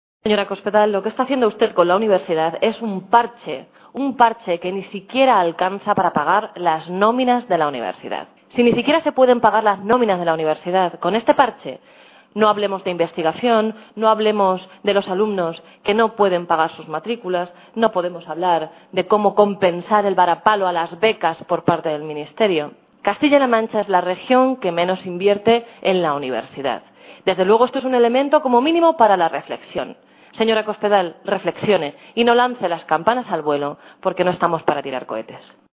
Blanca Fernández, diputada regional del PSOE de C-LM
Cortes de audio de la rueda de prensa